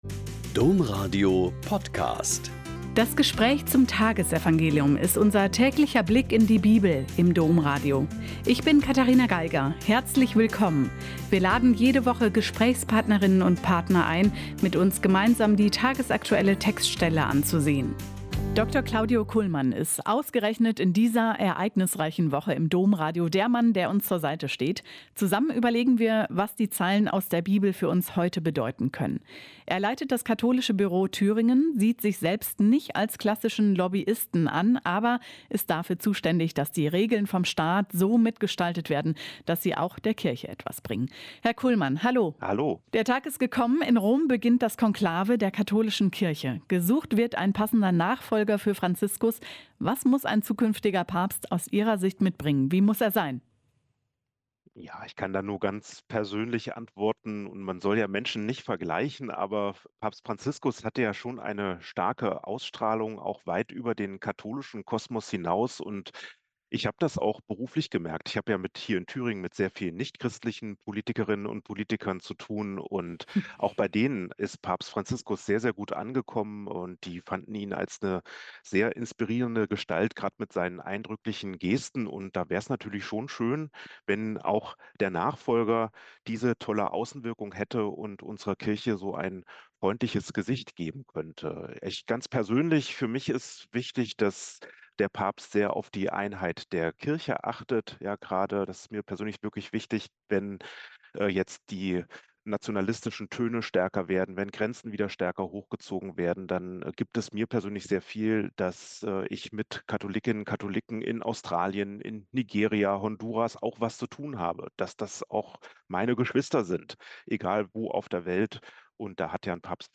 Joh 6,35-40 - Gespräch